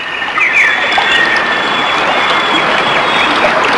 Birds By River Sound Effect
birds-by-river.mp3